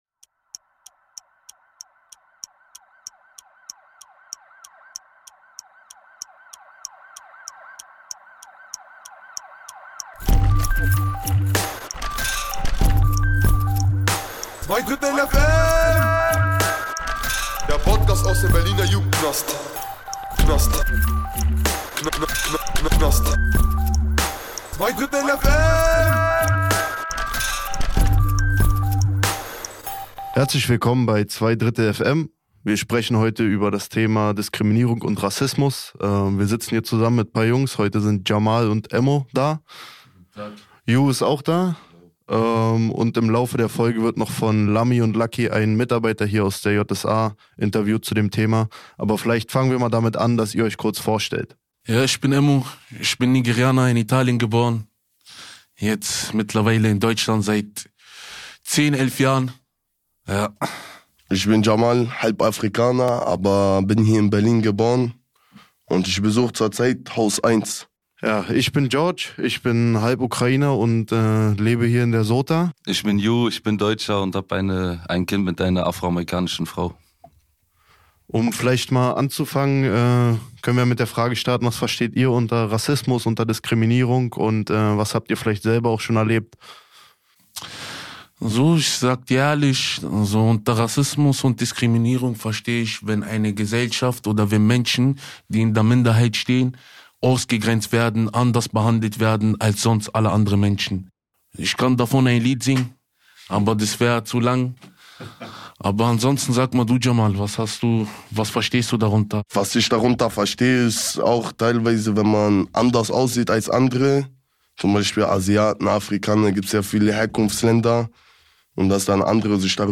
Die Crew von Zweidrittel FM diskutiert über ihre eigenen Erfahrungen mit Rassismus, Diskriminierung und Racial profiling. Selbstkritisch reflektieren sie auch eigene Vorurteile und befragen einen Mitarbeiter der Justiz zu seinen Eindrücken und Erlebnissen.